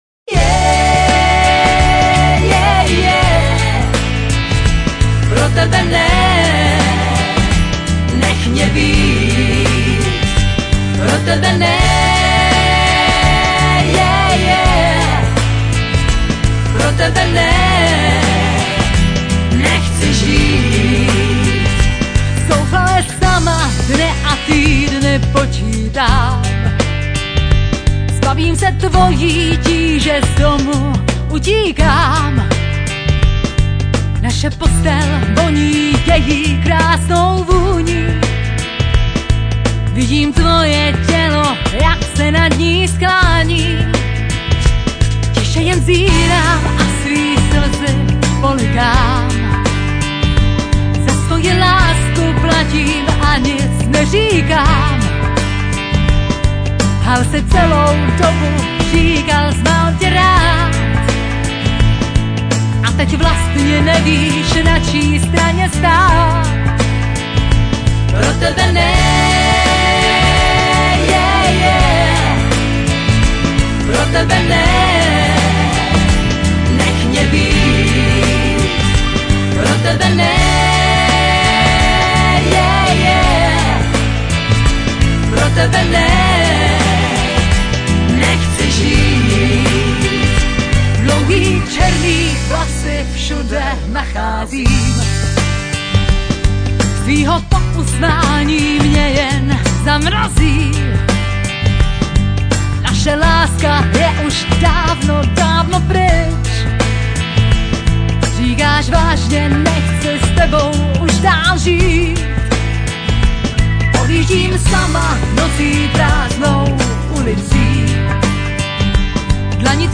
mastering:   Mercury studio Rokycany 2005-06,